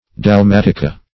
Dalmatica \Dal*mat"i*ca\, n., Dalmatic \Dal*mat"ic\, n.[LL.